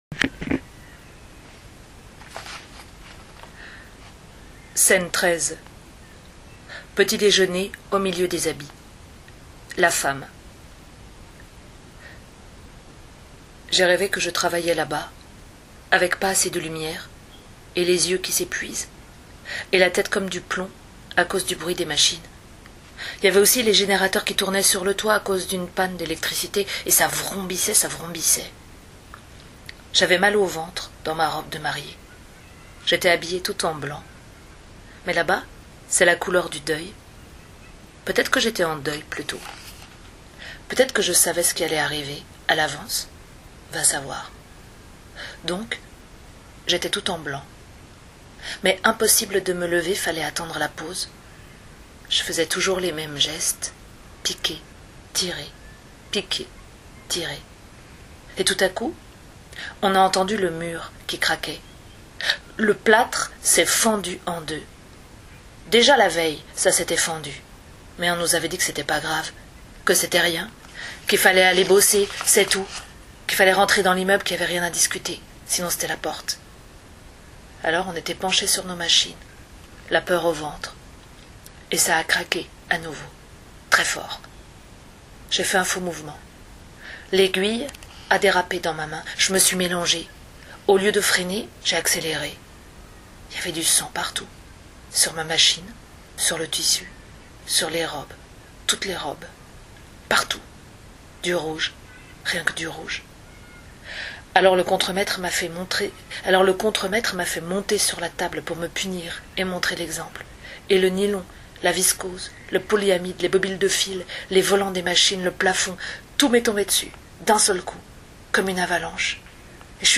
Comme convenu, je vous ai enregistré deux extraits de Comment on freine ?